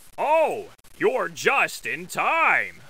Голос Балди и звуковые эффекты из игры для монтажа видео в mp3 формате